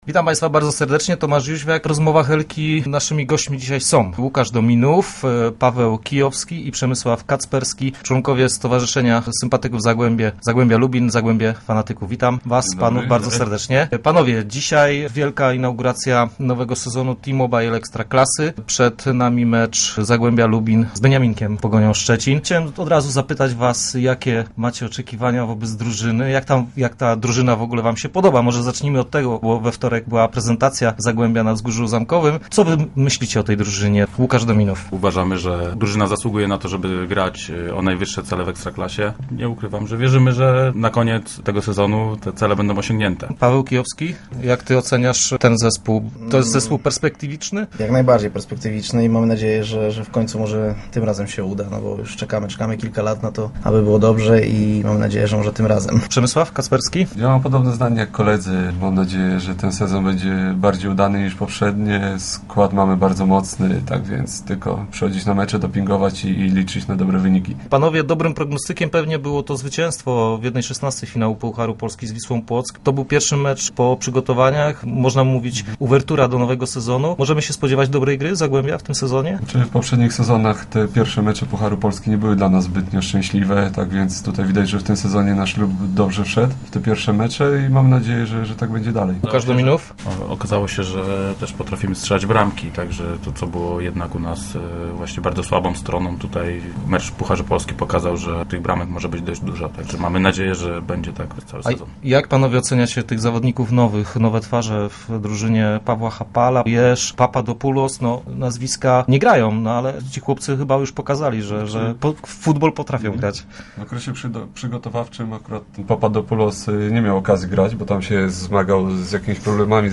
Start arrow Rozmowy Elki arrow Kibice chcą wielkiego Zagłębia
"Mamy perspektywiczny i mocny zespół", mówią fani "Miedziowych".